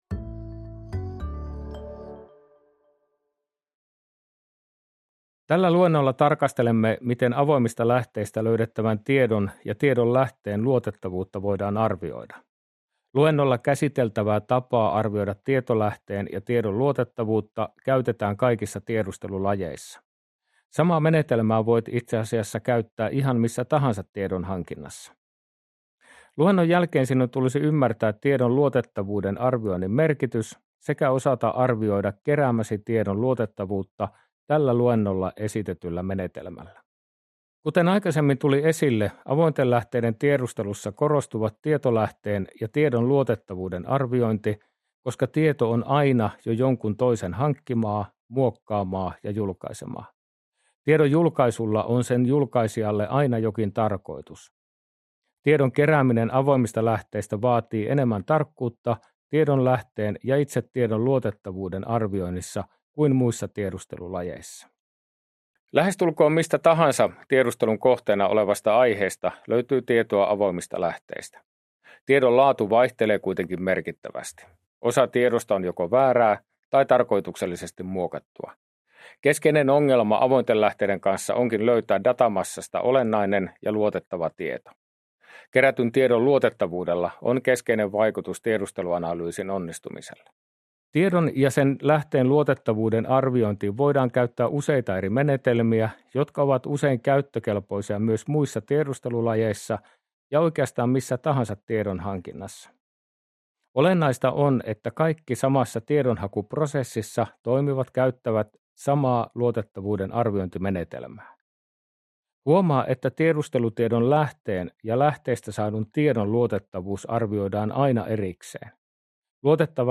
Luennon päivitetty versio lv 25-26 toteutukseen